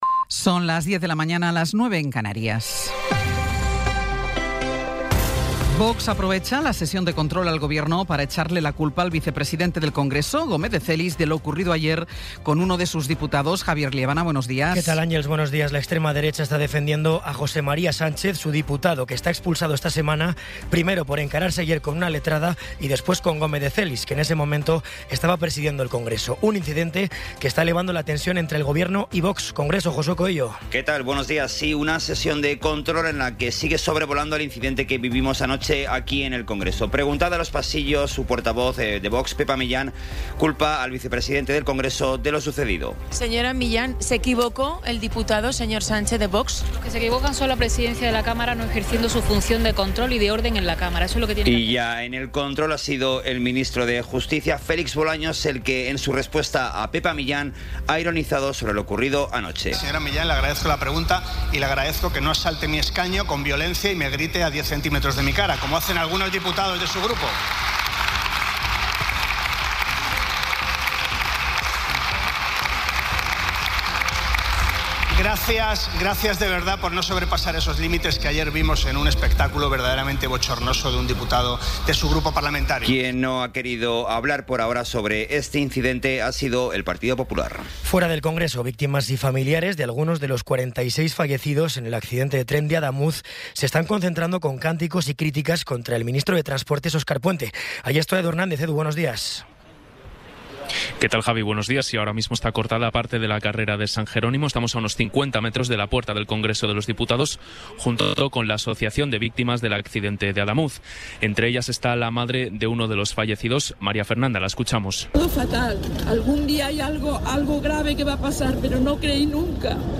Resumen informativo con las noticias más destacadas del 15 de abril de 2026 a las diez de la mañana.